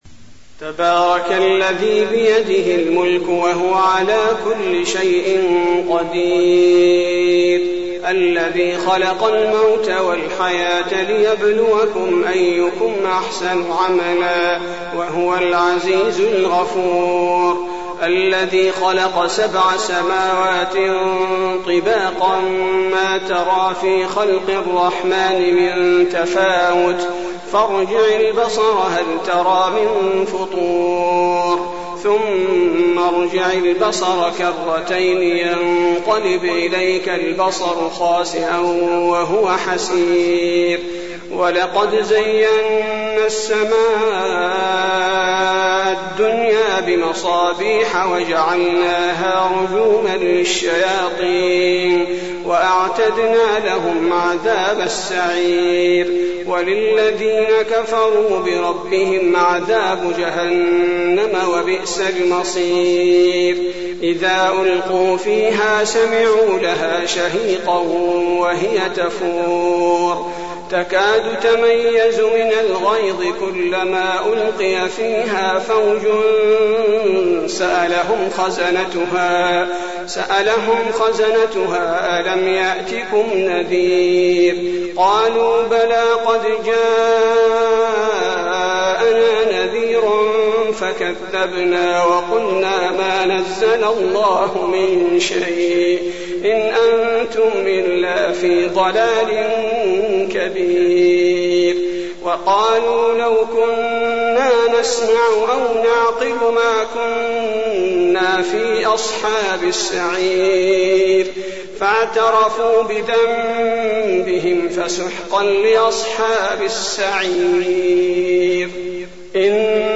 المكان: المسجد النبوي الملك